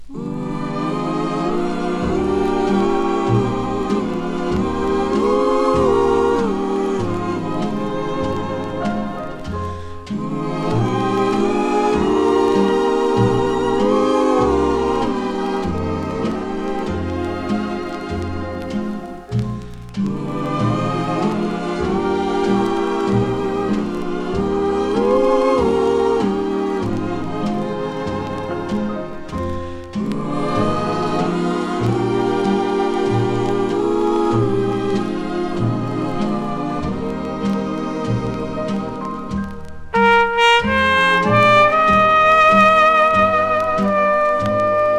Jazz, Pop, Easy Listening　USA　12inchレコード　33rpm　Mono